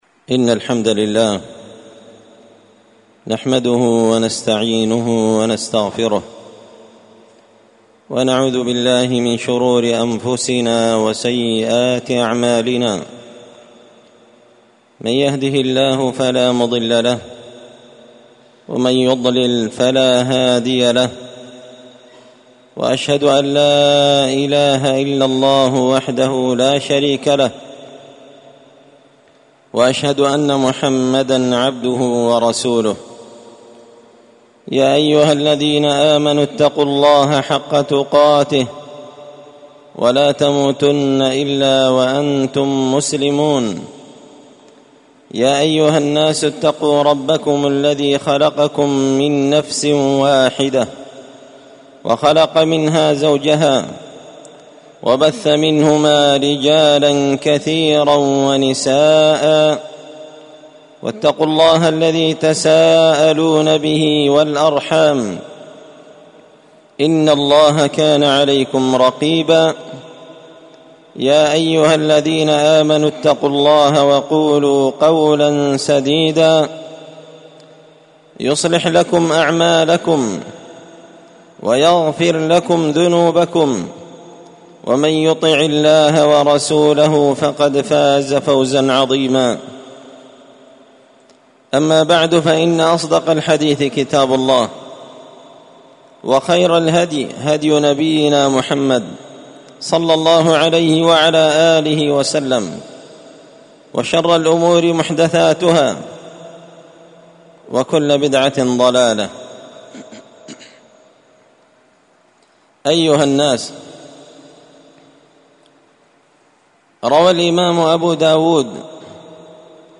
خطبة جمعة بعنوان: